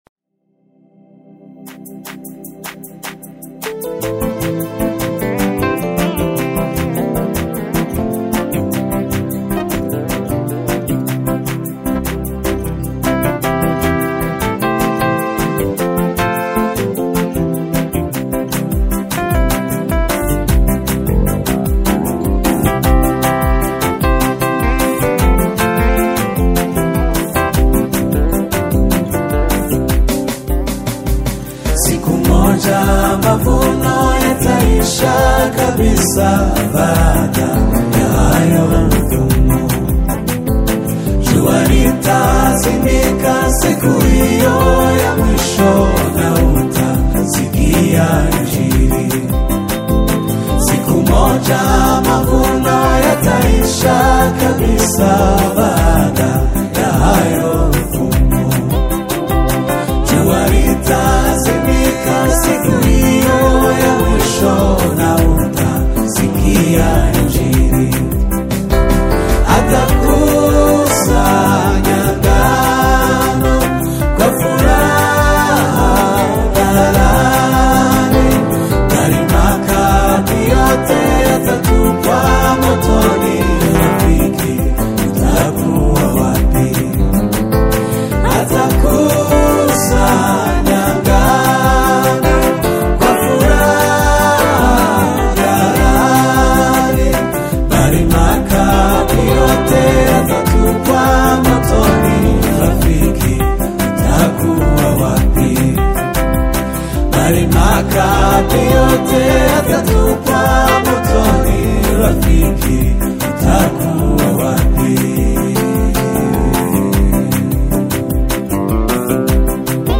The exquisitely harmonized and spiritually vibrant single
fluid, emotive piano work